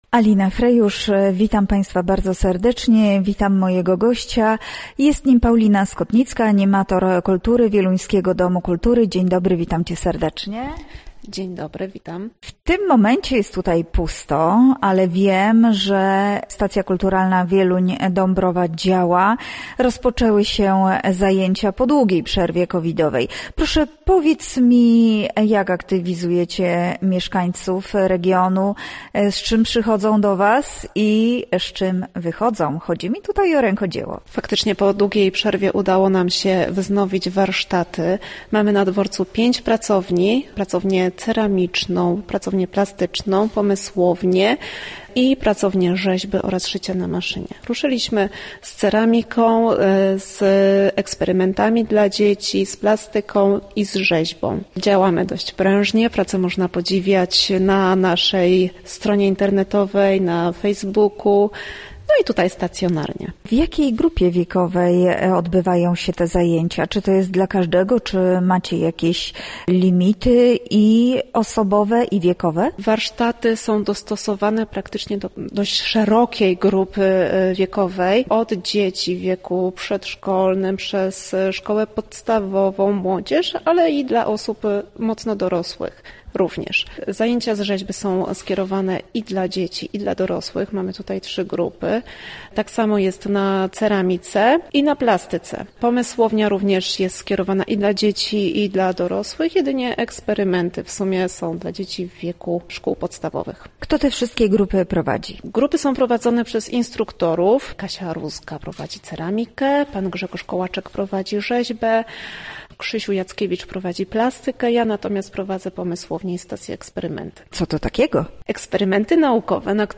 Pięć pracowni warsztatowych, kameralna sala koncertowa i oranżeria – w Stacji Kulturalnej Wieluń – Dąbrowa po długiej przerwie spowodowanej pandemią rozpoczęły się zajęcia artystyczne. Będą również koncerty i wystawy. Nasz gość mówi o tych wydarzeniach i zaprasza do uczestnictwa.